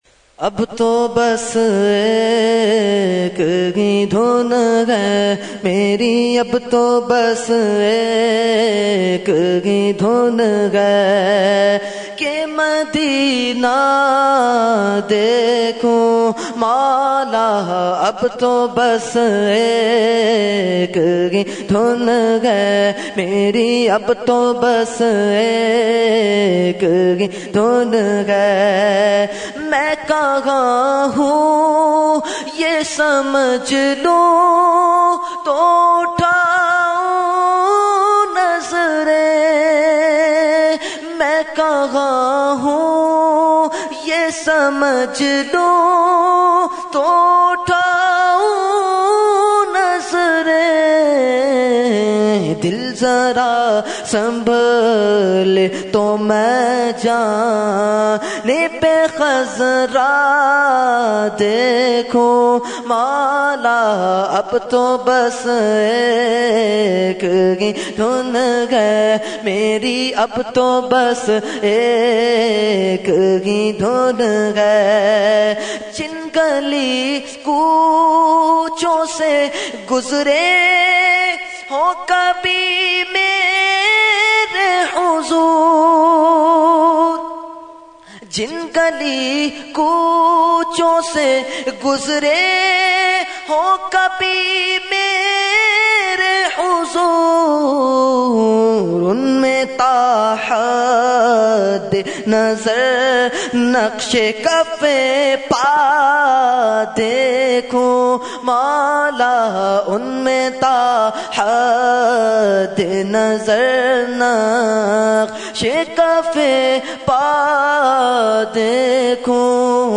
Category : Naat | Language : UrduEvent : Shab e Meraaj 2014